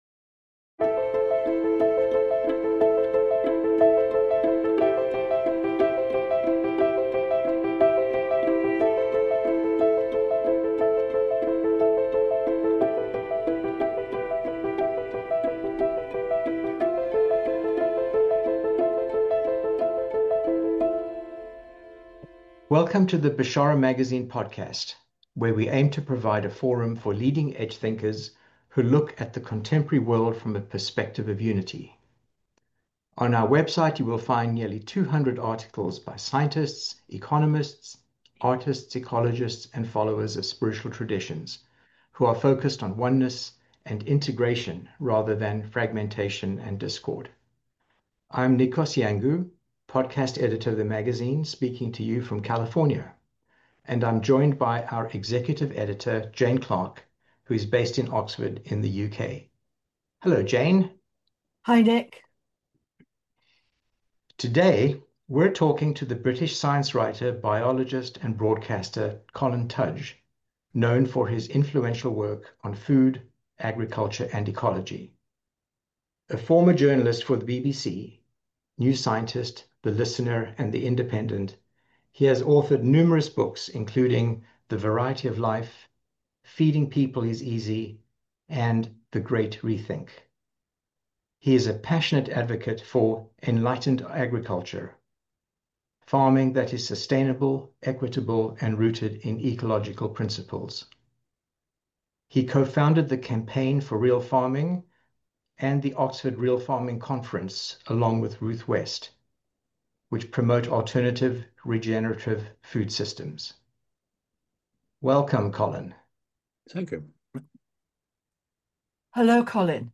An Interview with Colin Tudge